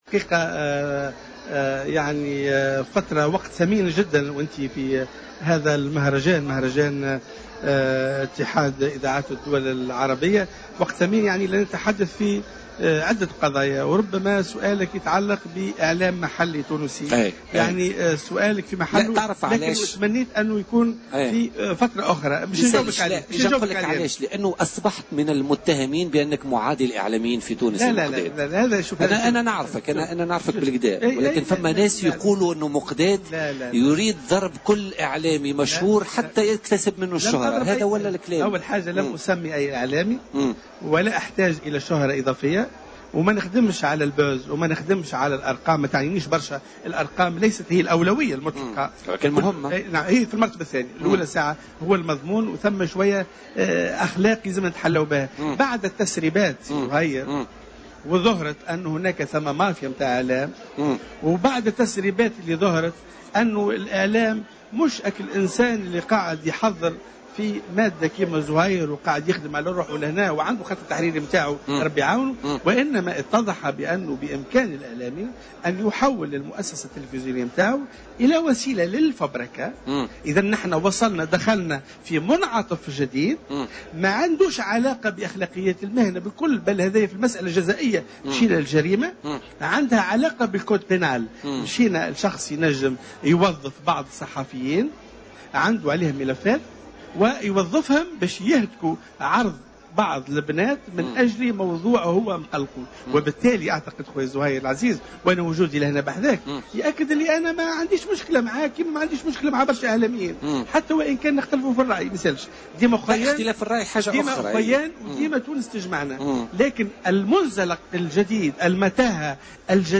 في حوار مع الجوهرة أف أم